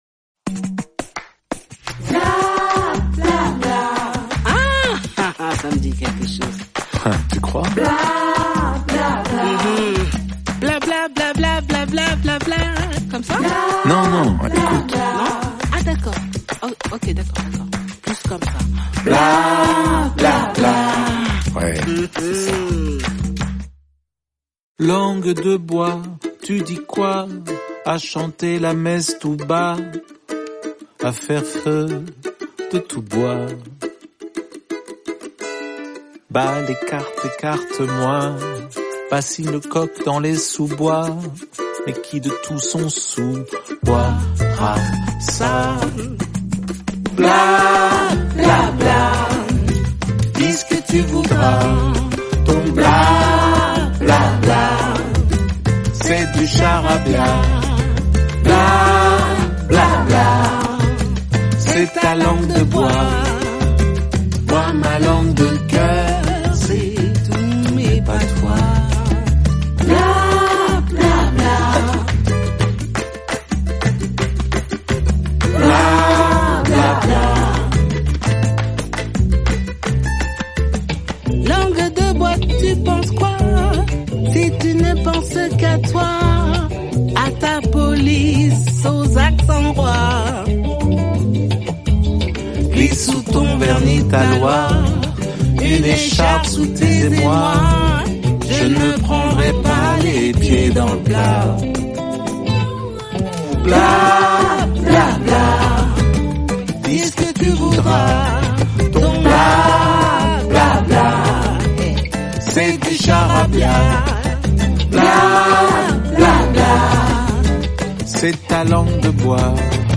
duo solaire et séduisant